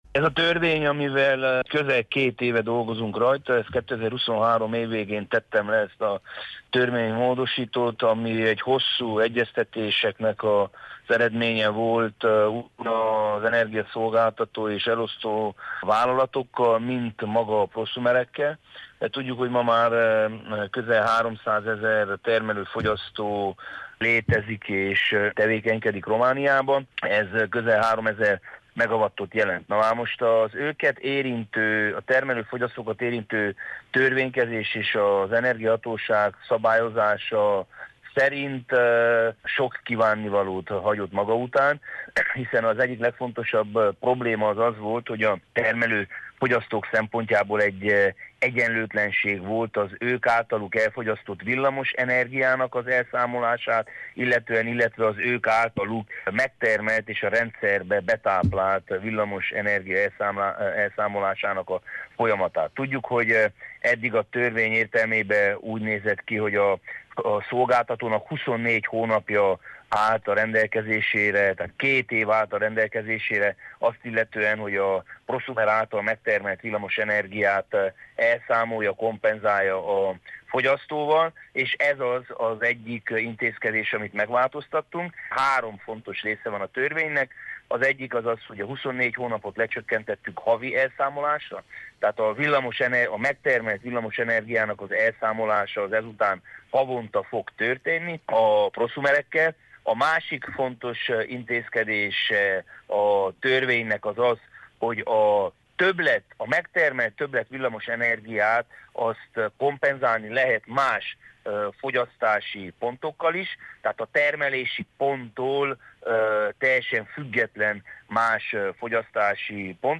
Antal Lóránt szenátort kérdezte